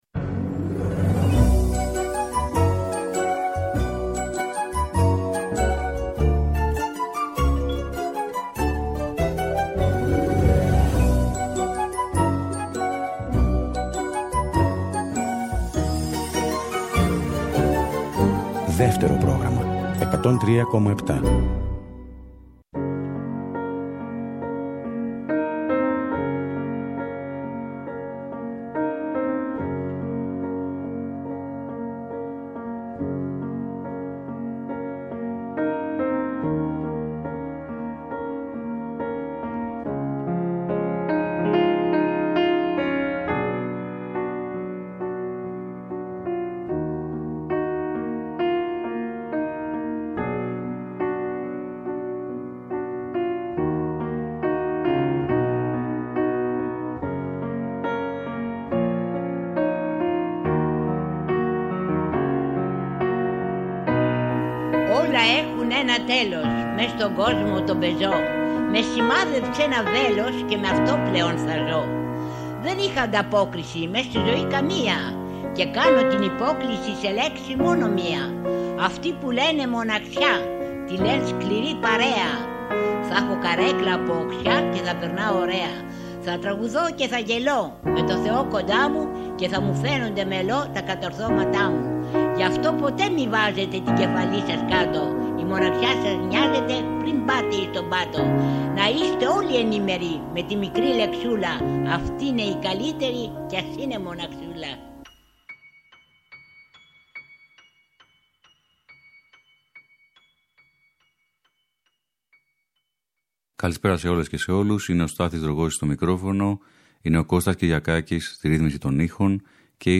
Τραγούδια διαχρονικά, καλοκαιρινά και αυγουστιάτικα